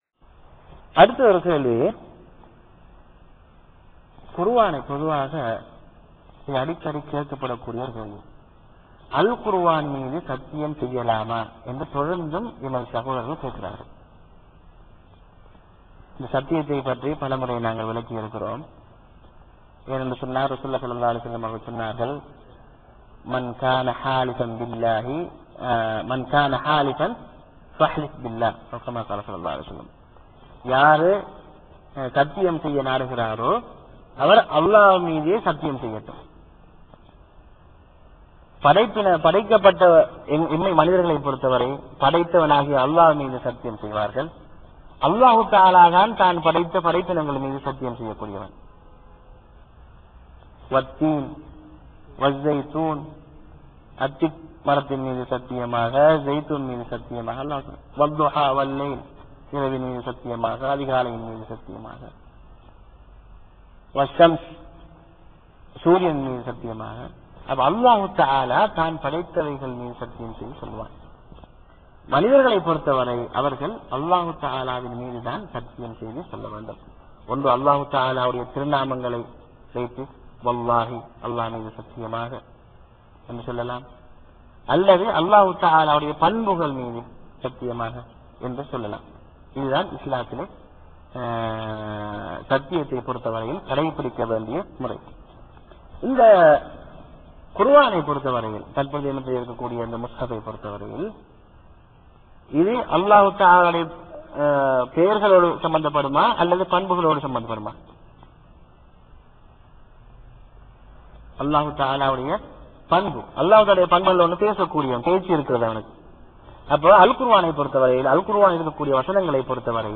வாராந்திர பயான் நிகழ்ச்சி (கேள்வி-பதில் பகுதி)
இடம் : அல்-கப்ஜி, சவூதி அரேபியா